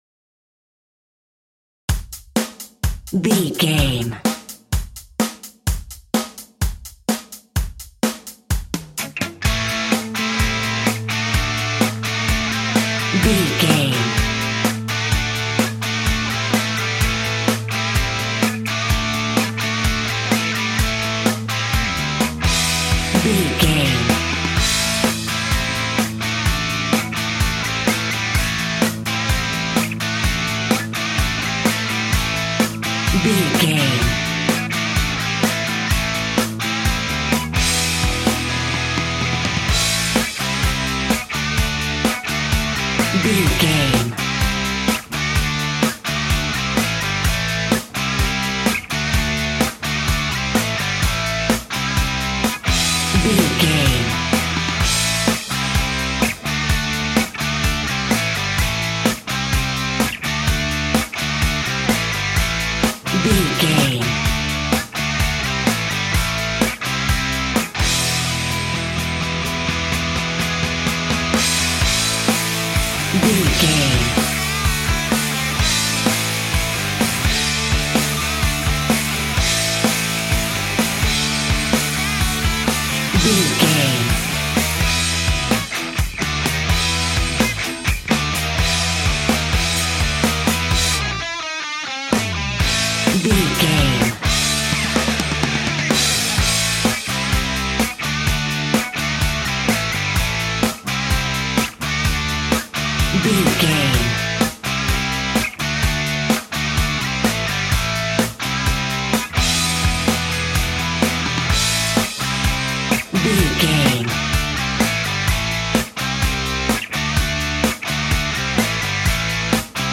Ionian/Major
energetic
driving
heavy
aggressive
electric guitar
bass guitar
drums
hard rock
heavy metal
distortion
distorted guitars
hammond organ